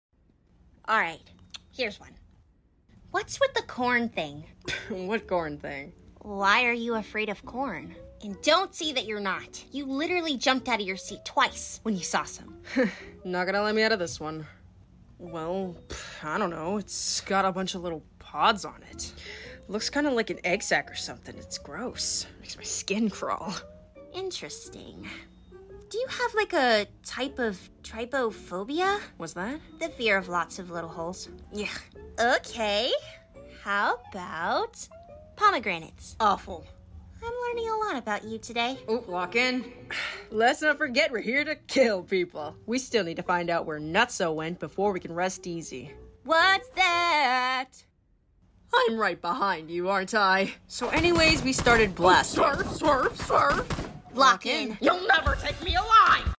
⚡I was giggling while recording sound effects free download